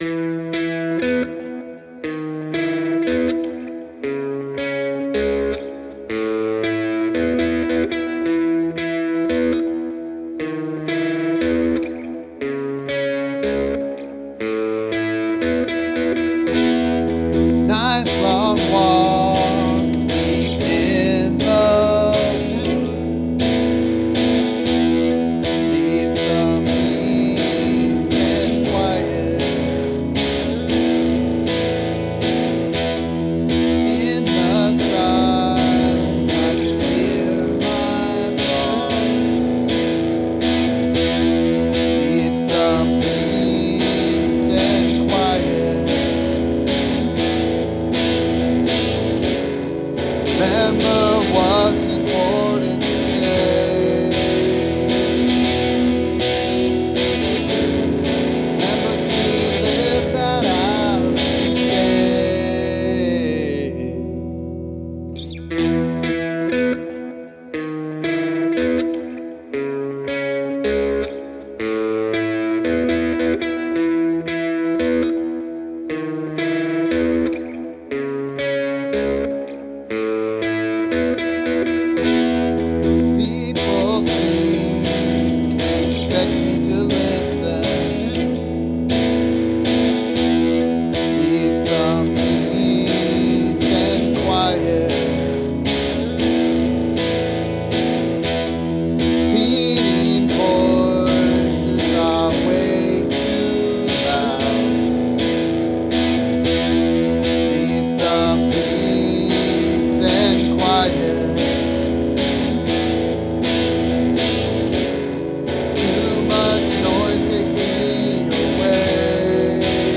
Note that I don't know how to produce any music, sorry about the quality and lack of mixing.